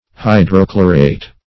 Hydrochlorate \Hy`dro*chlo"rate\, n.